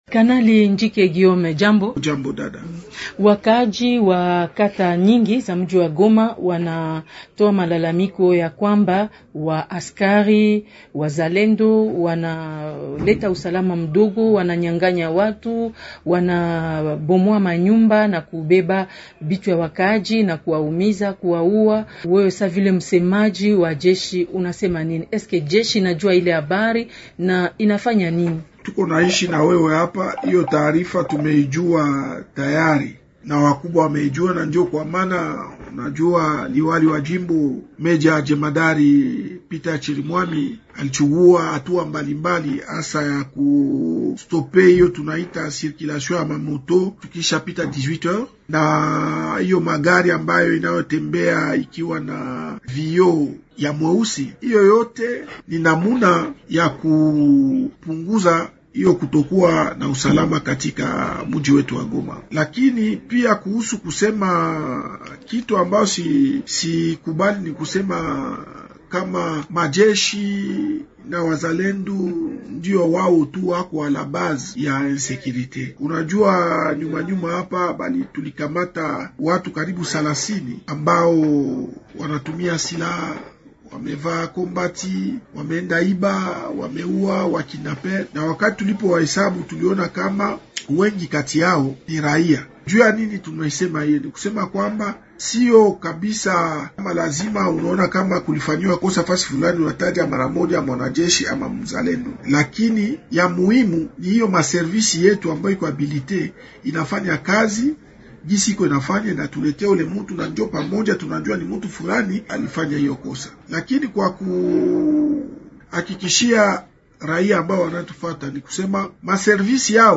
L'invité swahili, Émissions / Institut Supérieur de Management, ISM, étudiants